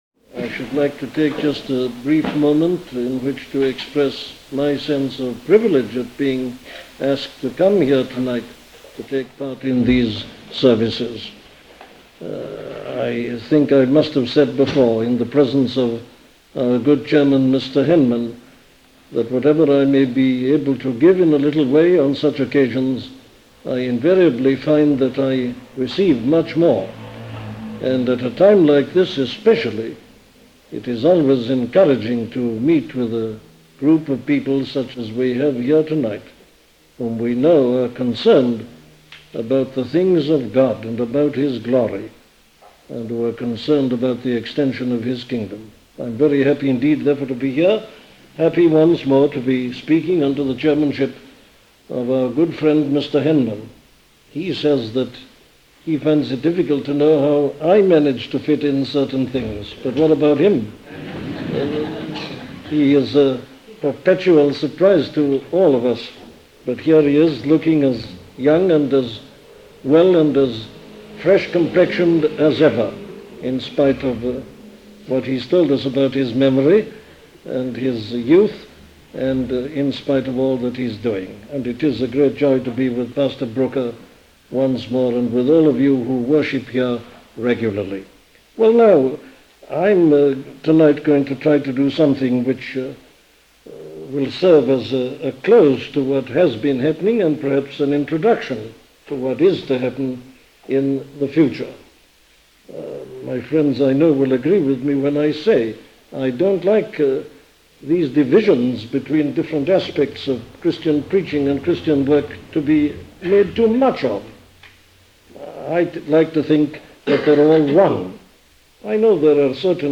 A collection of sermons on Itinerant Preaching by Dr. Martyn Lloyd-Jones
An audio library of the sermons of Dr. Martyn Lloyd-Jones.